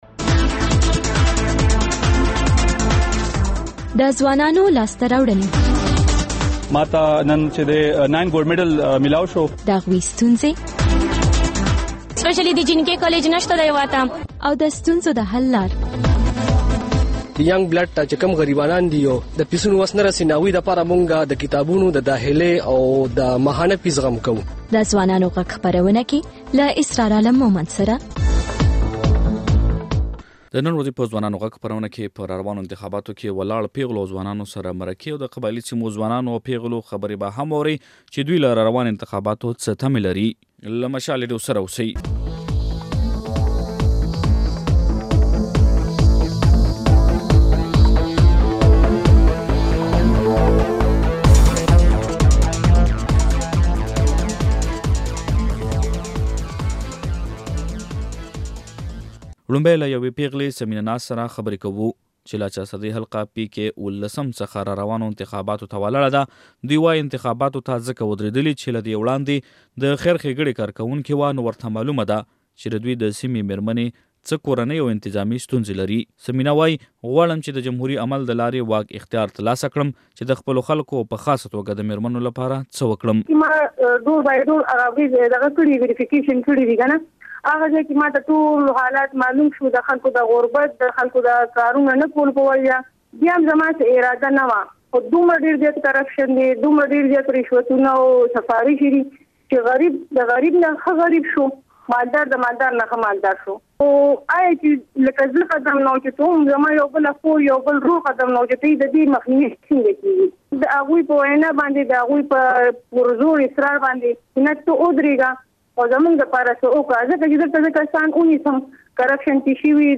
د ځوانانو غږ خپرونه کې په پاکستان کې د را روانو انتخاباتو په اړه له پیغلو او ځوانانو سره مرکې لرو او د قبايلي سيمو ځوانانو خبرې